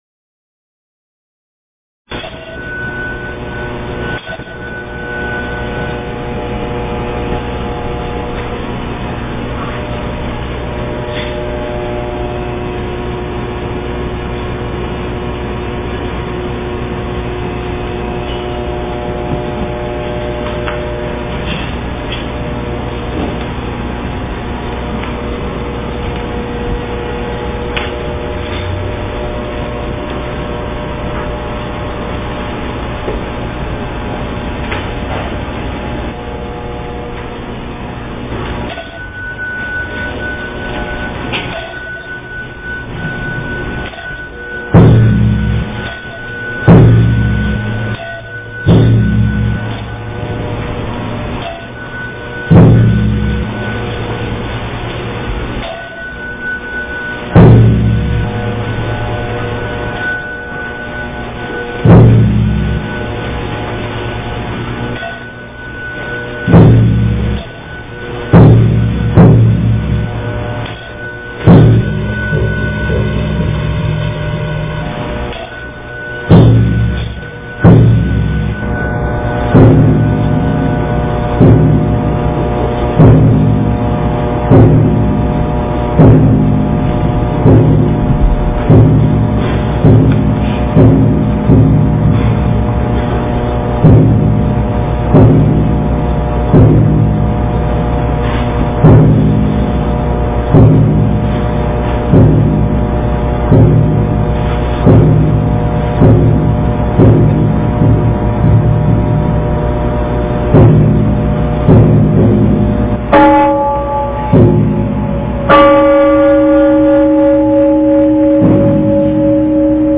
午课--大华严寺 经忏 午课--大华严寺 点我： 标签: 佛音 经忏 佛教音乐 返回列表 上一篇： 早课--灵泉禅寺版 下一篇： 阿弥陀佛-超长版--精进佛七 1小时 相关文章 准提咒--海涛法师 准提咒--海涛法师...